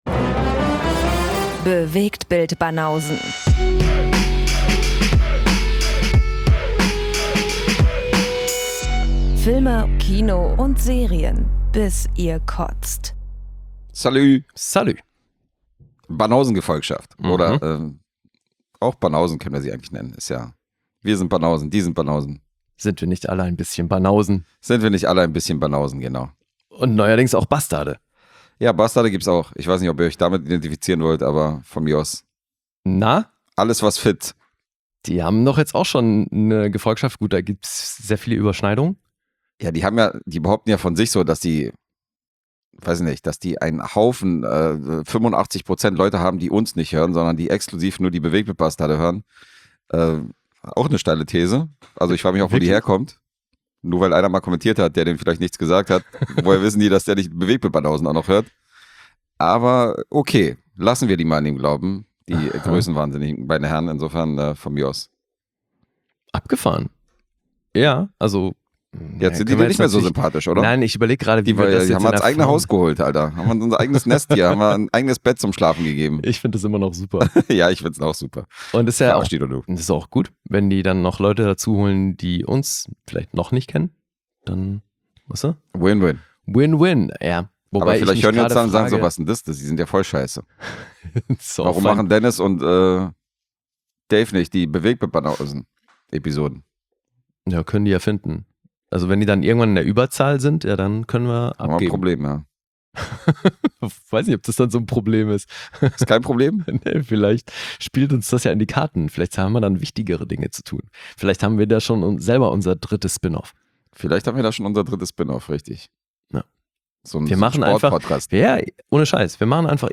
Weihnachtszeit ist ja auch eine Zeit des Gebens und Schenkens, und genau eure aufgehängten Socken werden in dieser Episode mit einigen Überraschungen gefüllt. Alle anderen können gern die Rezensionen genießen, die wie immer mit unfassbarer Verve von uns vorgetragen werden.